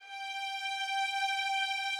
Added more instrument wavs
strings_067.wav